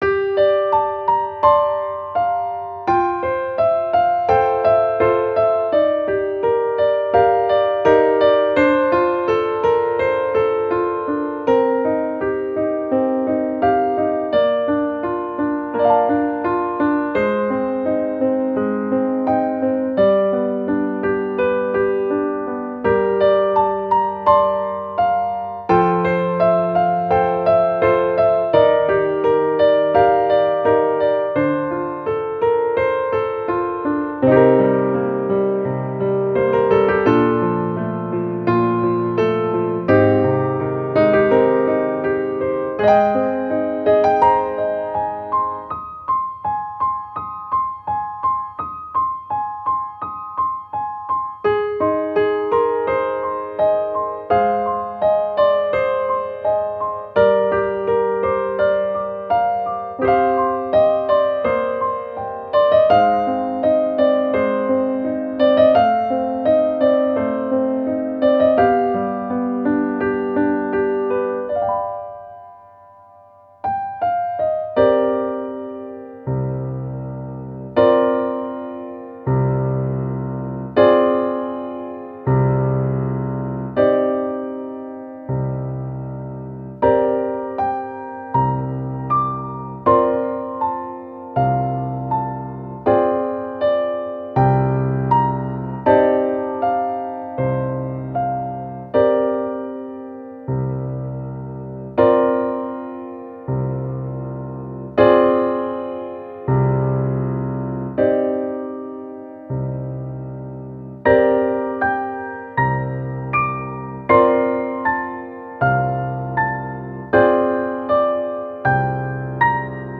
-oggをループ化-   しっとり 寂しい 2:22 mp3